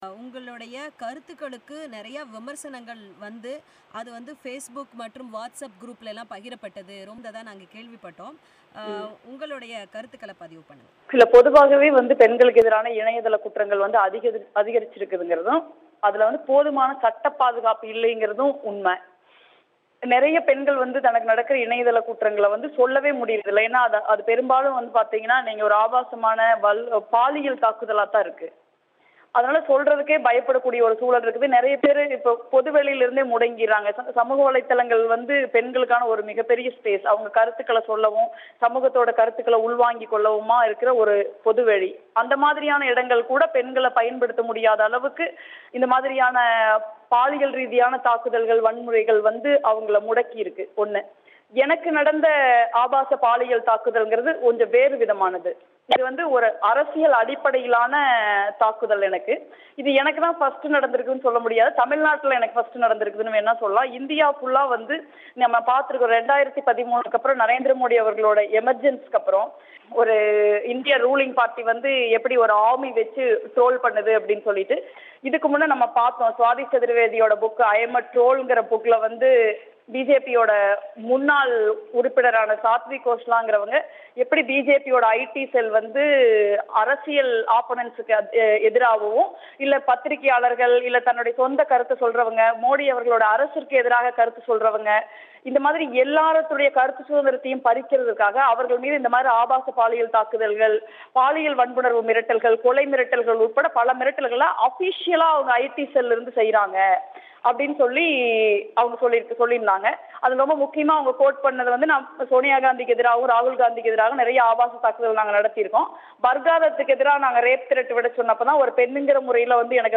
அவர் பிபிசிக்கு அளித்த பேட்டி